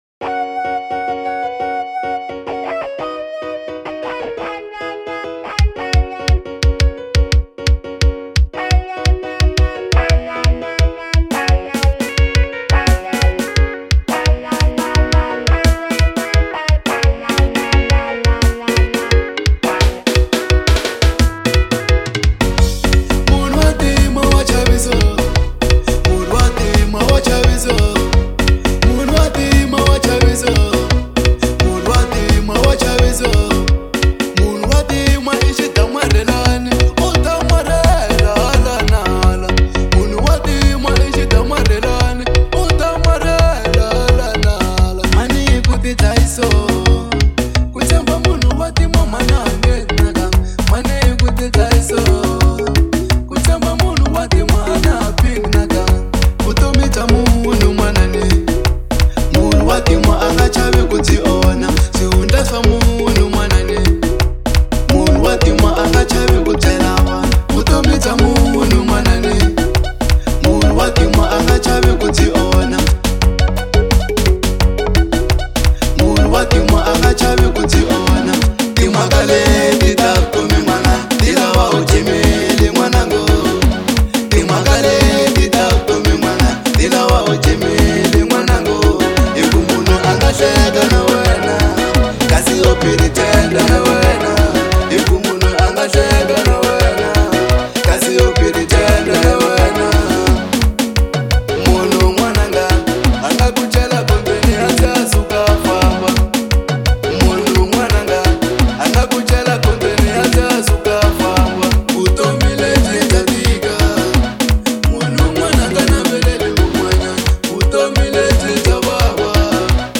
04:06 Genre : Xitsonga Size